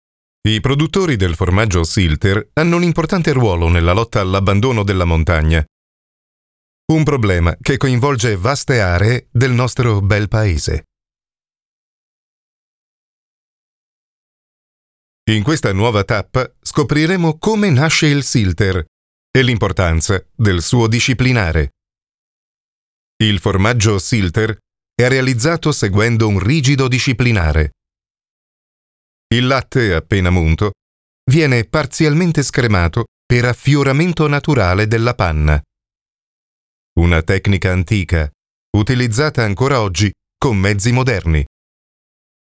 Vídeos Explicativos
Posso descrever minha voz como uma voz masculina de "meia-idade", profunda, calorosa, comunicativa, para documentários ou onde o sentimento é necessário.
Barítono